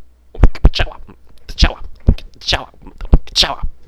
SOUND chowa
Like other Brobbits, Rinf has been unable to grasp the fundamentals of human language, communicating only with his characteristic and rhythmic chirps and gurgles.